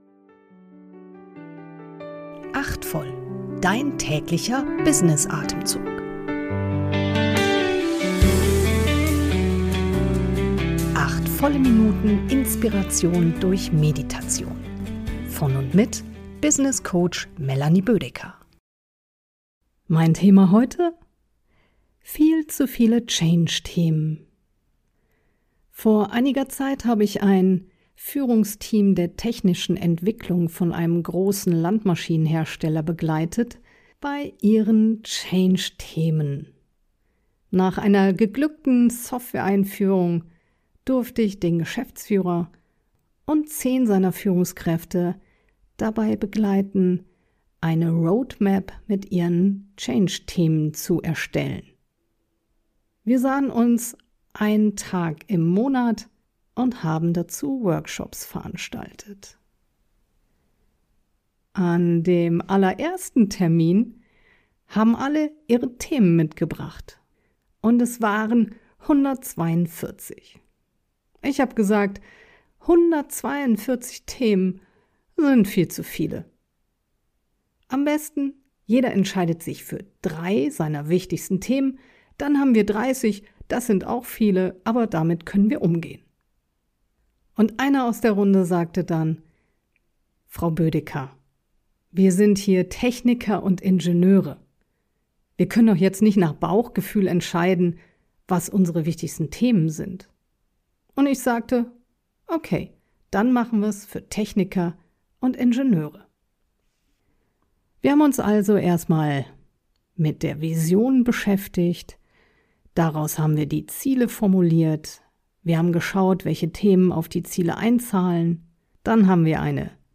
durch eine geleitete Kurz-Meditation.